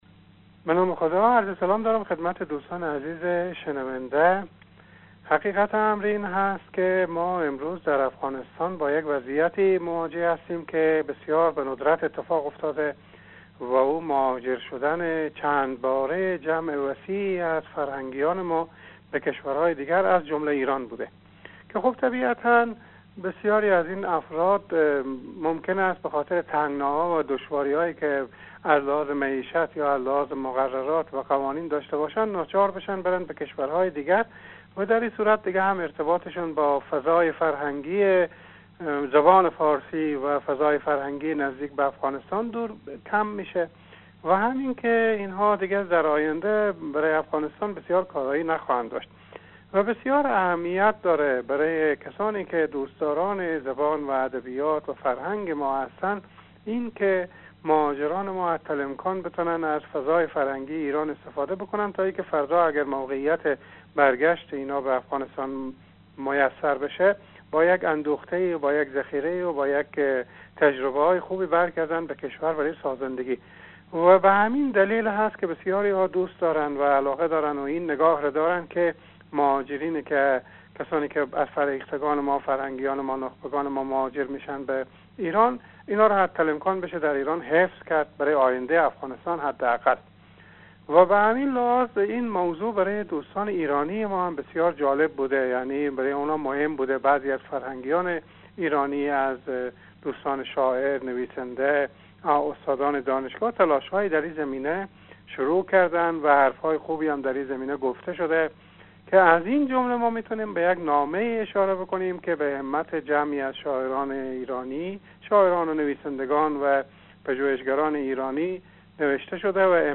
گفت و گو با خبرنگار رادیو دری